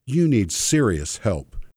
I have already reduced this p-pop somewhat by cutting small sections from the waveform, but if I cut much more I’ll lose the ‘p’ sound or at least turn in into something that sounds more like a ‘b’ than a ‘p’.
You could also try moving the microphone and/or pop-shield positions a little - the popping does not seem too bad, but it may still be catching some wind blast from your P’s.
There’s just a bit of “sub-sonic wobble” of the waveform - if you have sub-woofers in your speaker system the “P” may flap your trouser legs due to the excess bass, but other than that it is not too bad.